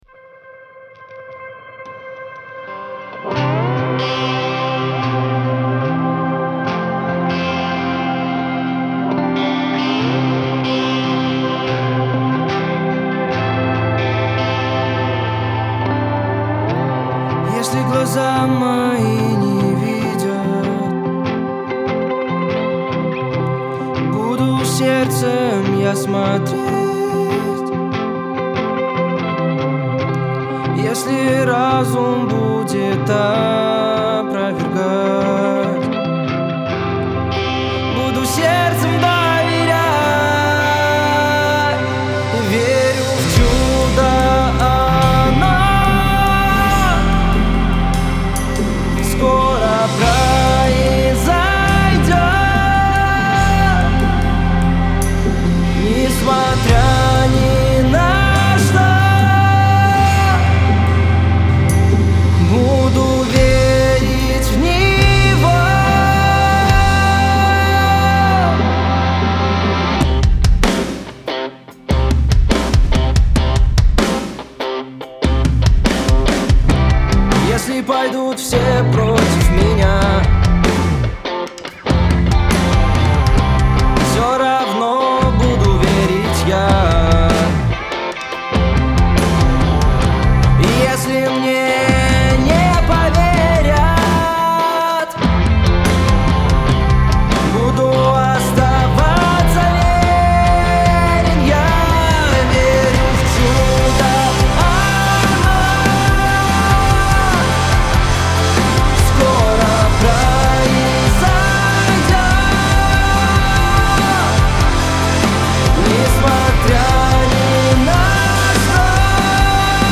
224 просмотра 194 прослушивания 21 скачиваний BPM: 143